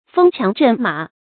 風檣陣馬 注音： ㄈㄥ ㄑㄧㄤˊ ㄓㄣˋ ㄇㄚˇ 讀音讀法： 意思解釋： 檣：船上用的桅桿。